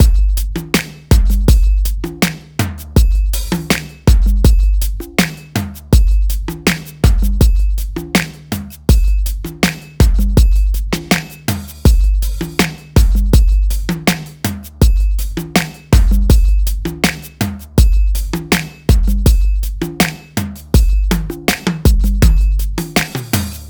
11 drums AC.wav